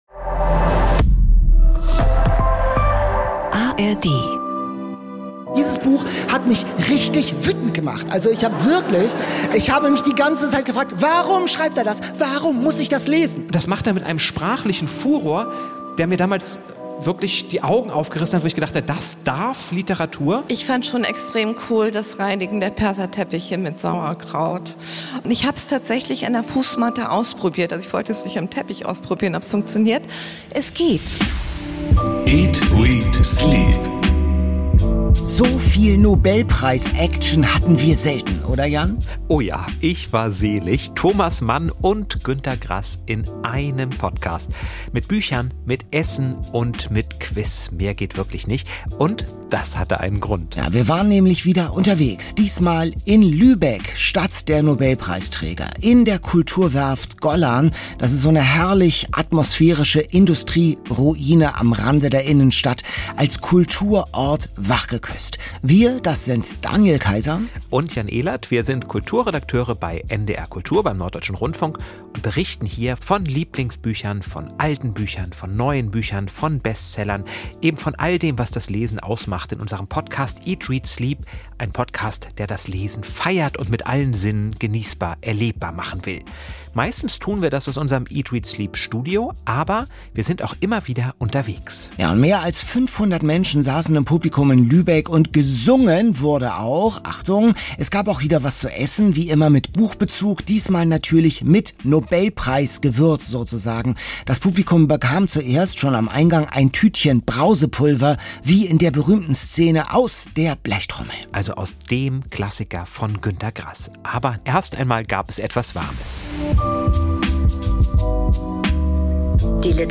Und beide finden reichlich Platz in dieser Folge, die in der Lübecker Kulturwerft Gollan aufgezeichnet wurde.